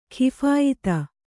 ♪ khiphāyita